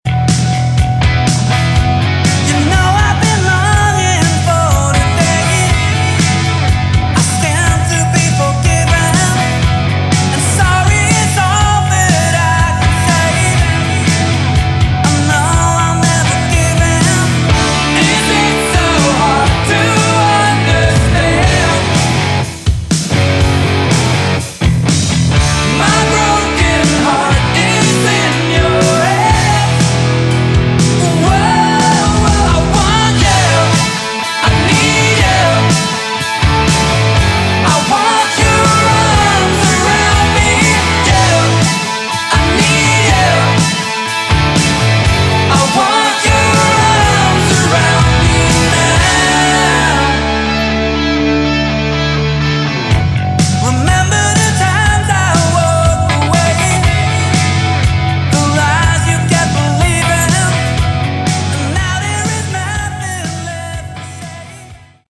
Category: Melodic Rock
Bass
Lead Vocals, Guitar
Drums
Keyboards